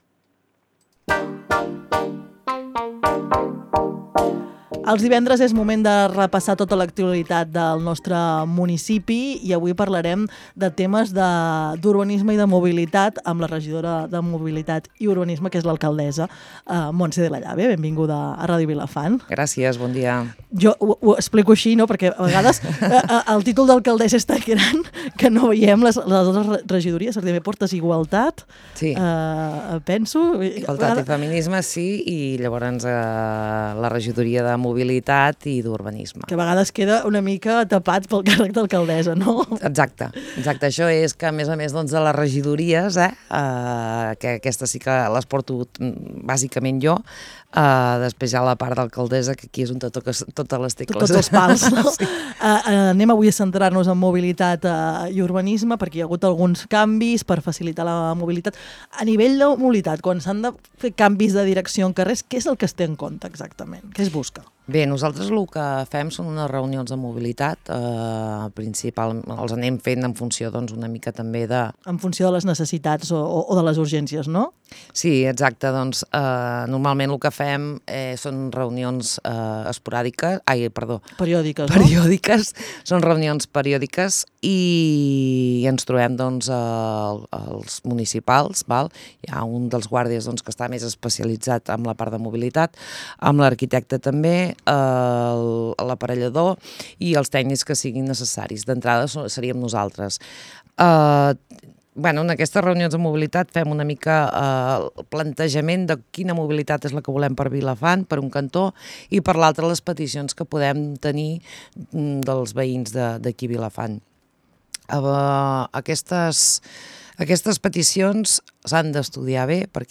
Parlem de mobilitat amb Montse de la Llave, alcaldessa i regidora de Mobilitat de Vilafant